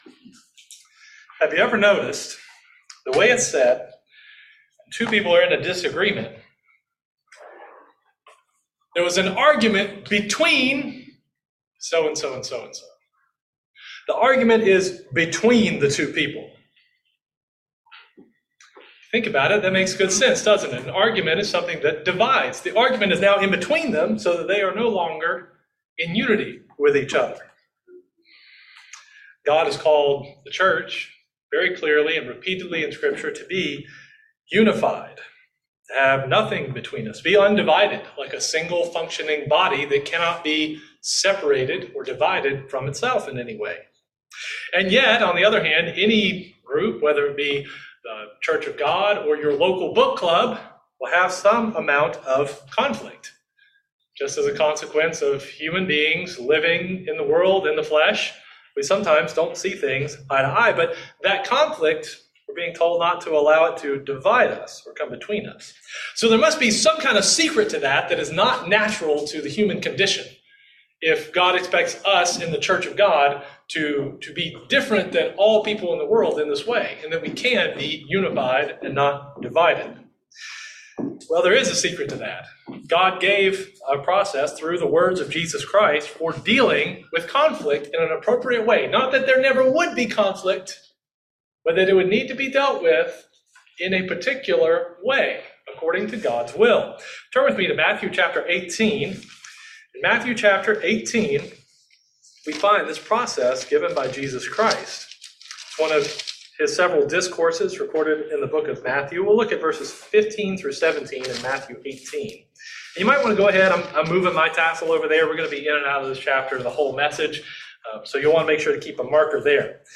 Sermon
Given in Central Georgia